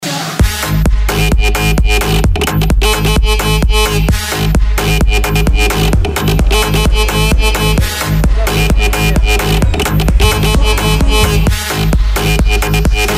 Очередная leadFX крякалка\скрипелка